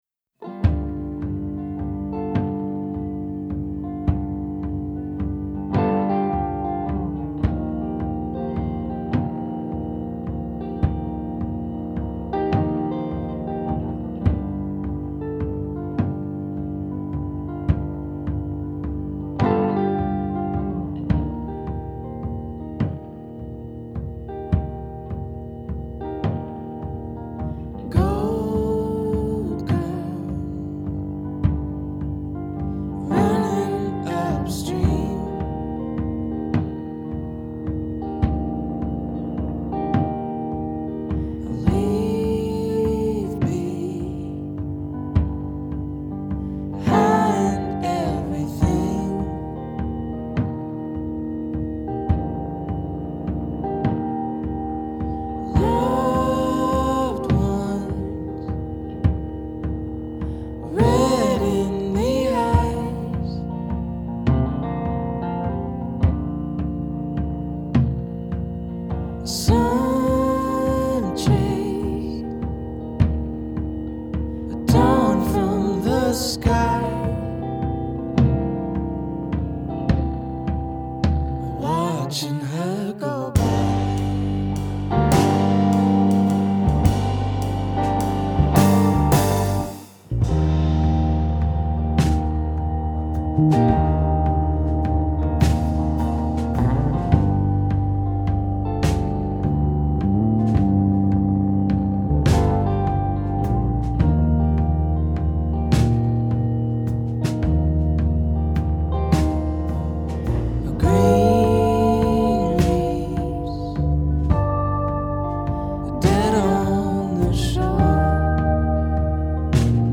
This use of duo-vocalists is deployed to great effect.
is a slow-building and ultimately raucous jam